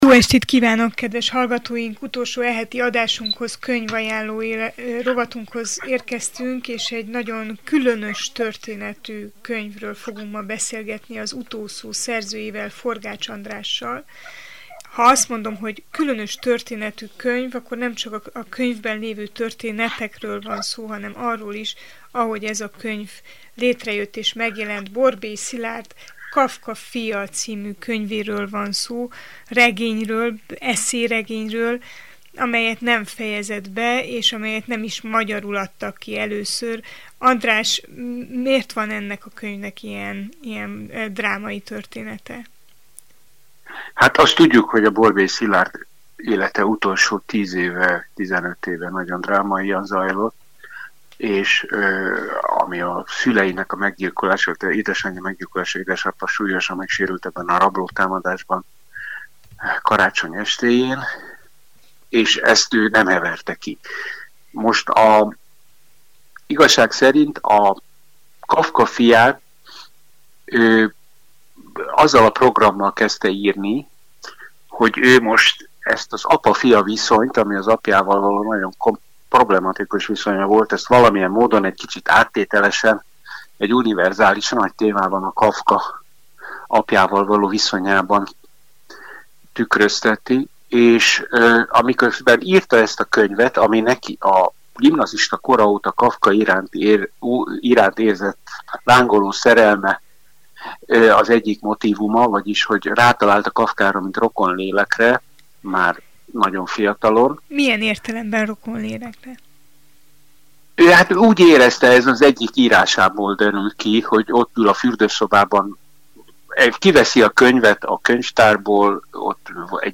Mennyire lehet "kinagyítani" az apáról való beszédet, metafizikai szintre emelni, és általános érvénnyel beszélni a legszemélyesebb, legellentmondásosabb, legbensőségesebb viszonyok egyikéről: az apa-fiú kapcsolatról? Borbély Szilárd regényéről, a Kafka fiáról Forgách Andrással beszélgettünk.